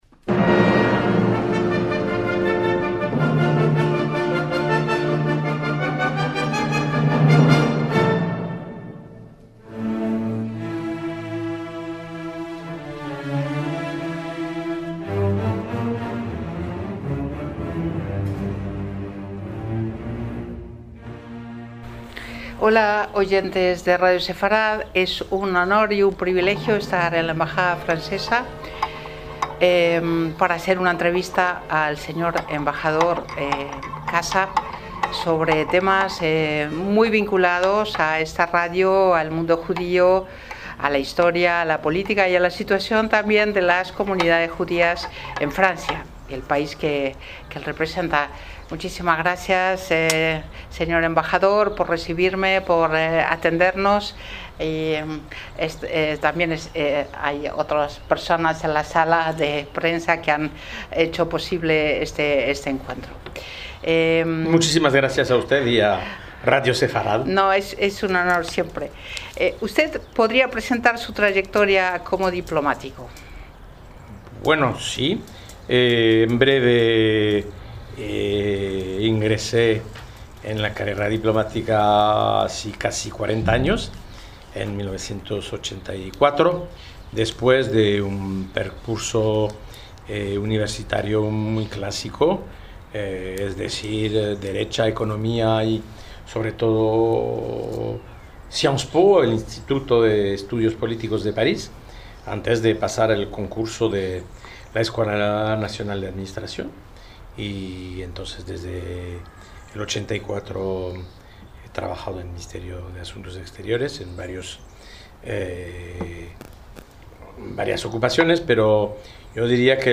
Entrevista al embajador de Francia en España, Jean Michel Casa